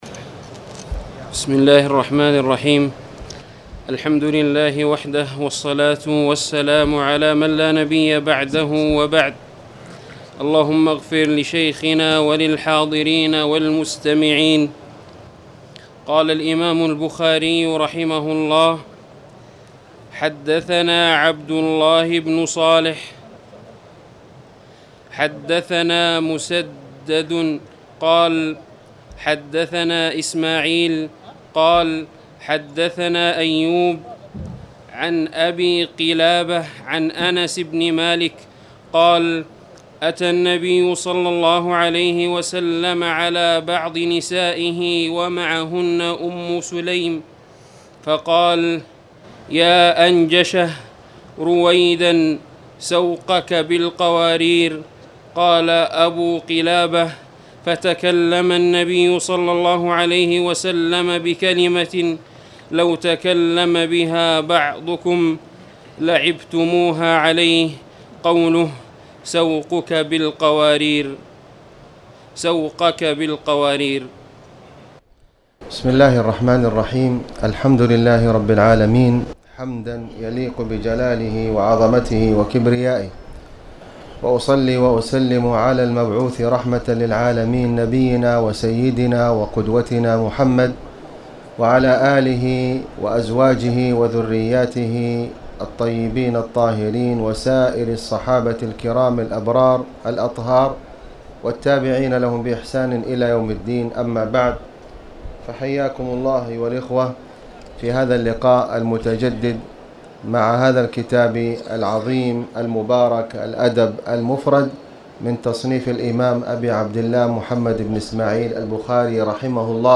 تاريخ النشر ٣٠ جمادى الأولى ١٤٣٨ هـ المكان: المسجد الحرام الشيخ: فضيلة الشيخ د. خالد بن علي الغامدي فضيلة الشيخ د. خالد بن علي الغامدي باب المزاح